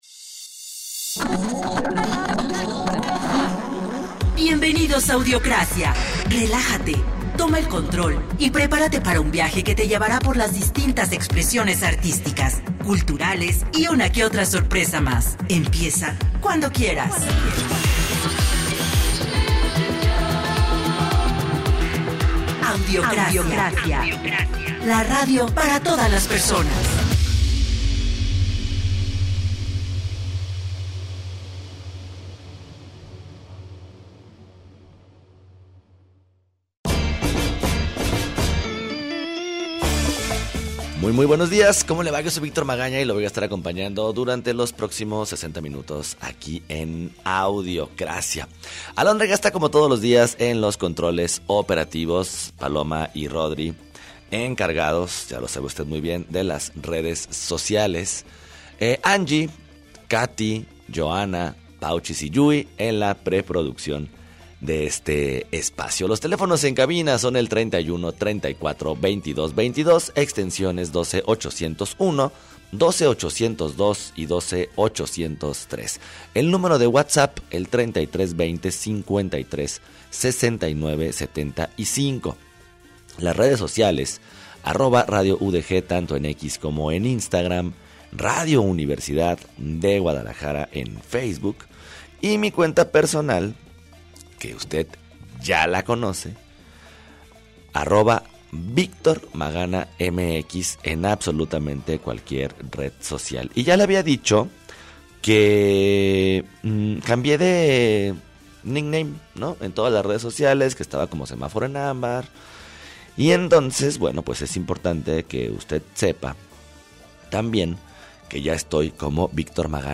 Música, conversación y muchas cosas más.